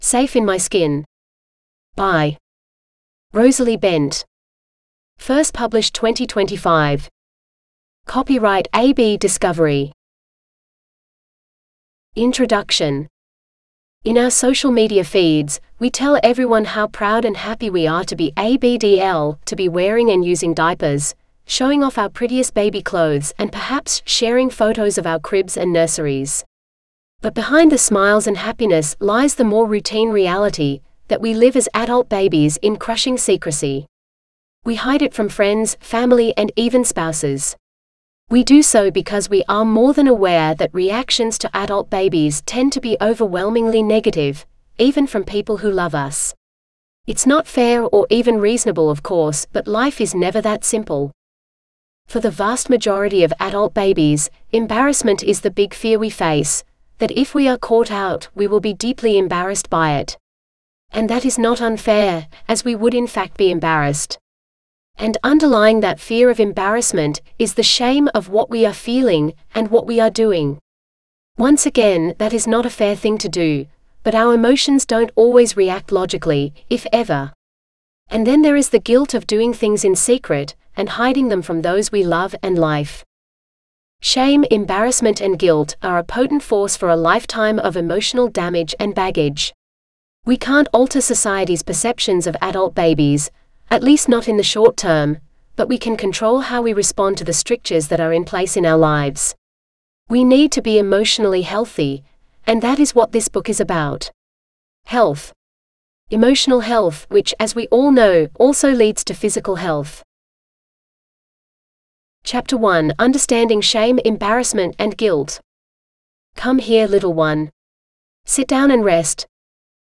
Safe In My Skin (AUDIOBOOK): $US5.75